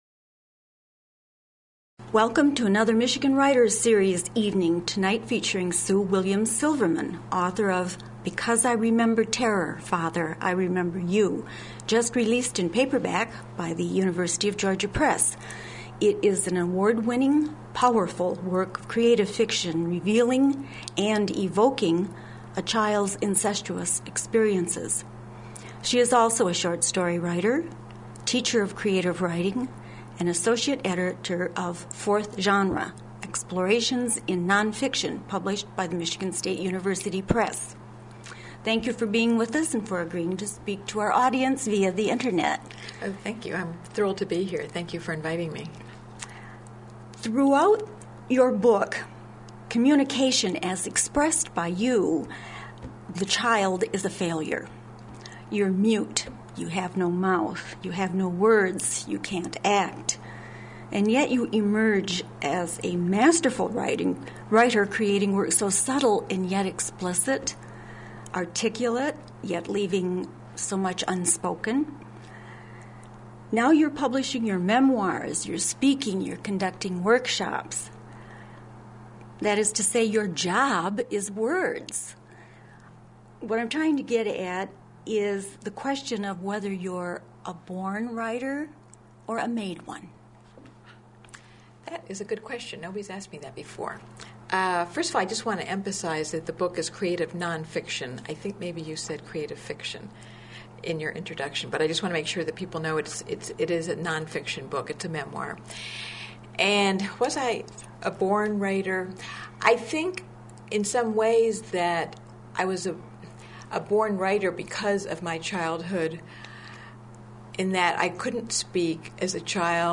interviews
Recorded as part of the MSU Libraries' Michigan Writers Series.